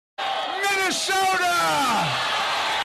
Joe Biden Screaming Minnesota